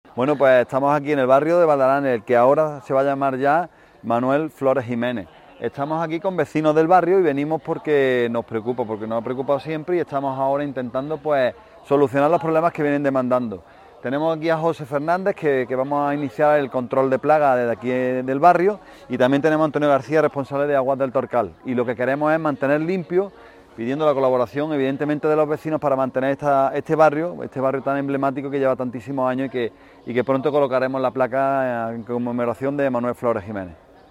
El teniente de alcalde Alberto Arana manifiesta su satisfacción por el inicio y desarrollo de esta campaña multidisciplinar que logrará mejorar, aún más si cabe, las acciones de limpieza viaria, desbroce de hierbas en el acerado público y el habitual control de plagas que se realiza ya de forma preventiva a estas alturas del año. Cortes de voz aarana_valdealanes 267.14 kb Formato: mp3